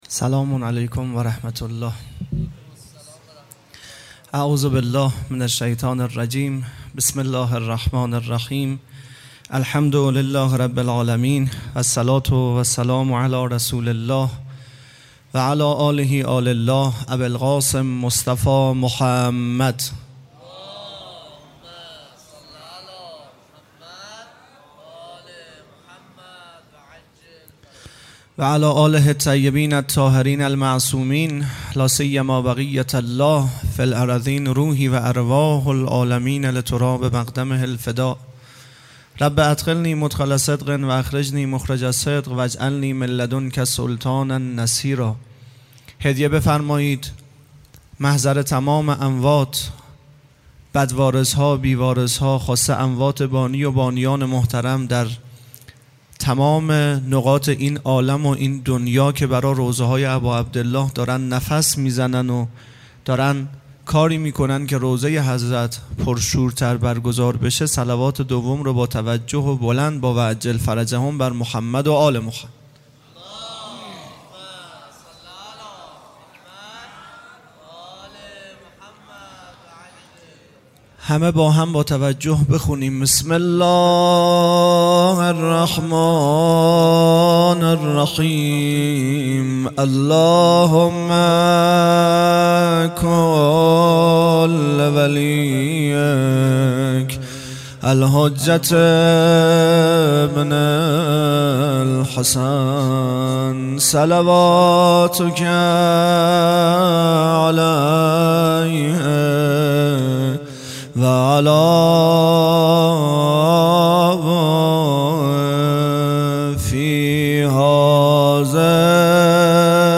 0 0 سخنرانی